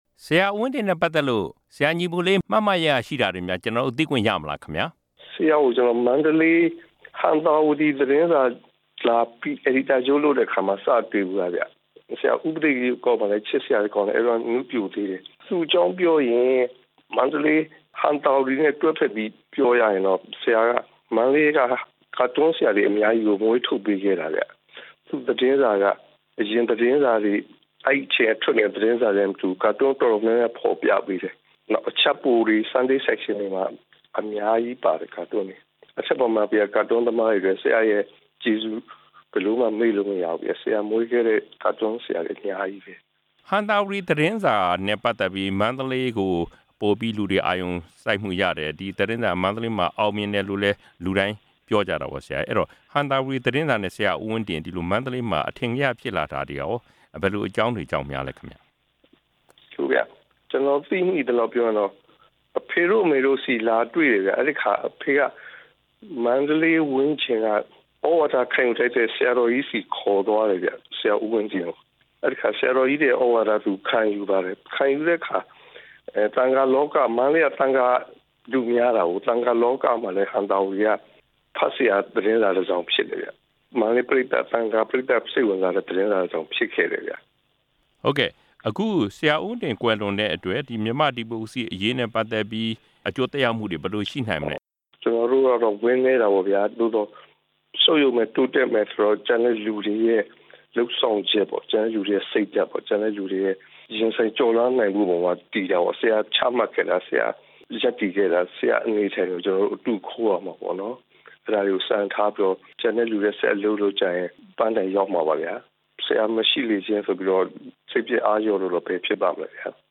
ဆရာ ဦးဝင်းတင်နဲ့ ပတ်သက်ပြီး စာရေးဆရာ ညီပုလေးနဲ့ ဆက်သွယ်မေးမြန်းချက်